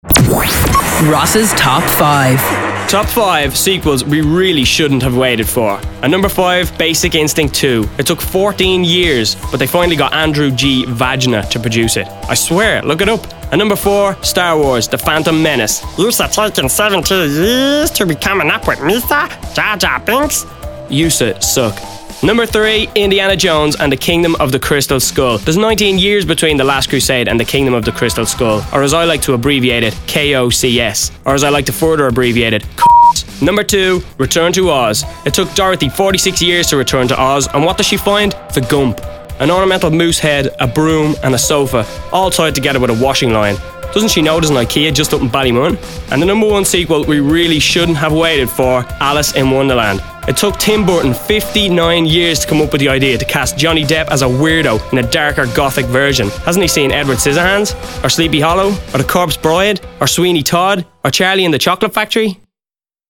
Nice Jar Jar Binks impersonation, too 😀